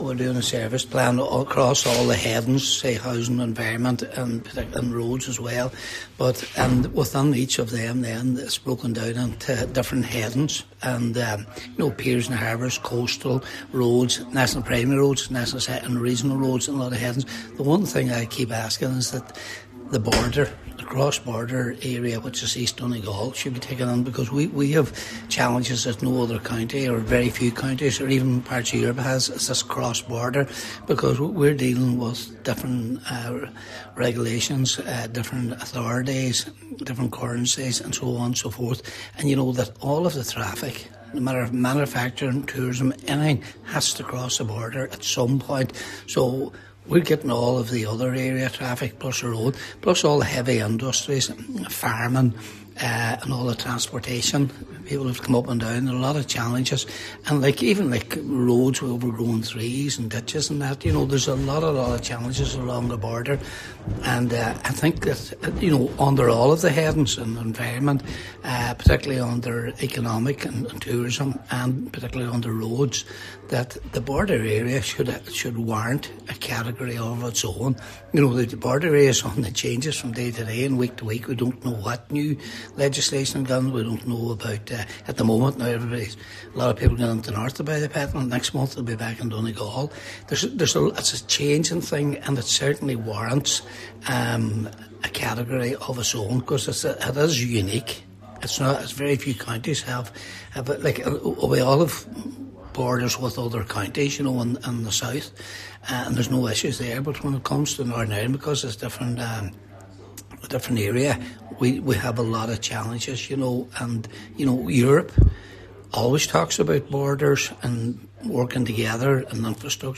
You can listen to Cllr McGowan’s full conversation